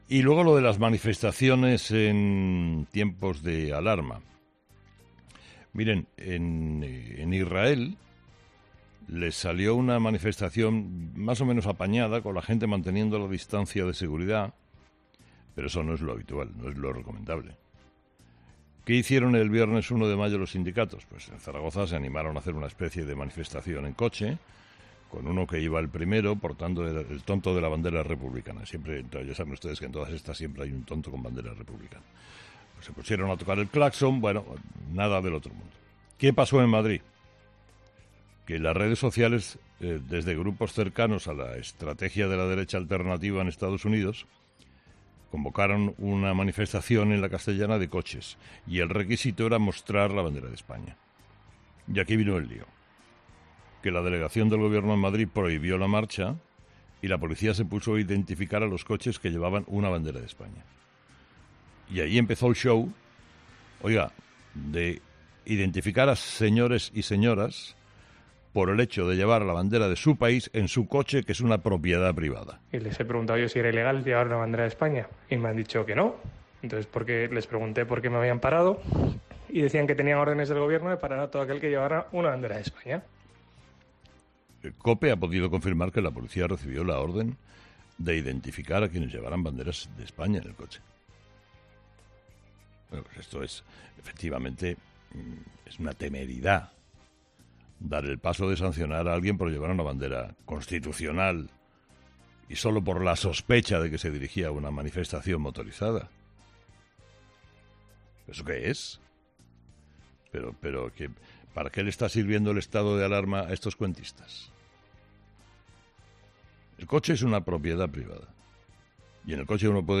En su monólogo de este lunes, Herrera ha analizado esta situación.